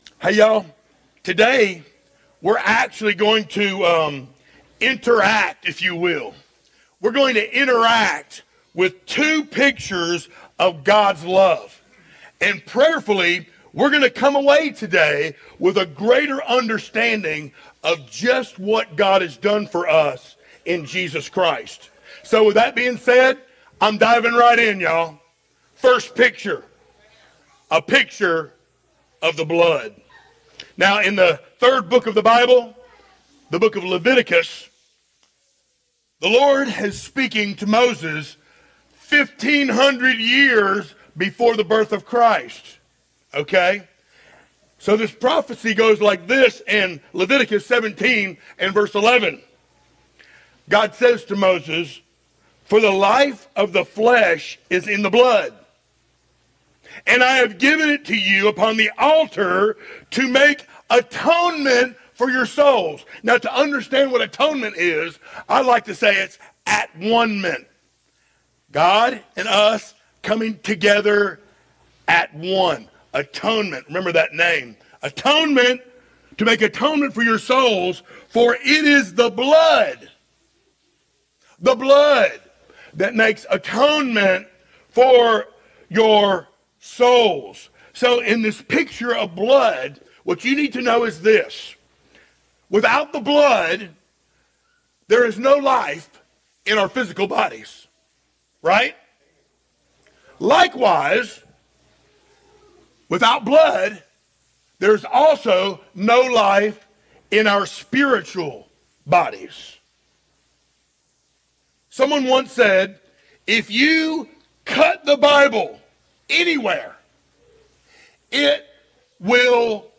Series: sermons
58 Service Type: Sunday Morning Download Files Notes « The Faithful Few EASTER “Living in the Present